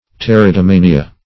Search Result for " pteridomania" : The Collaborative International Dictionary of English v.0.48: Pteridomania \Pter`i*do*ma"ni*a\, n. [Gr.